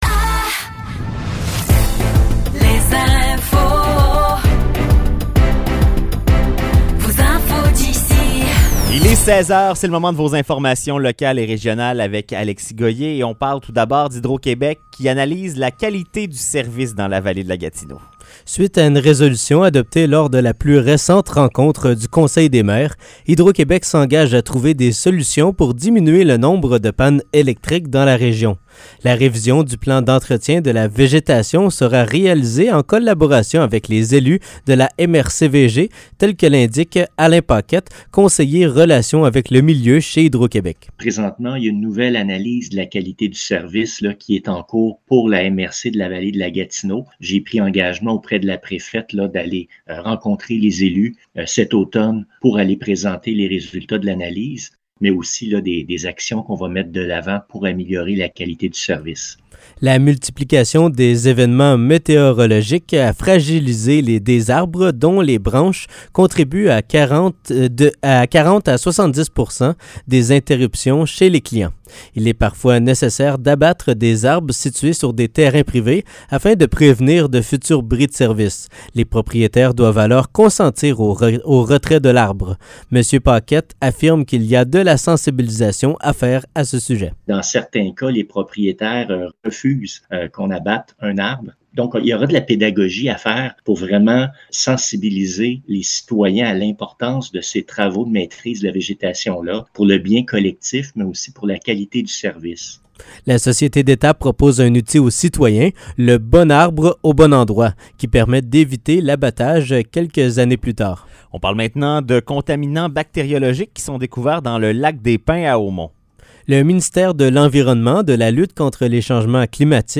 Nouvelles locales - 14 septembre 2023 - 16 h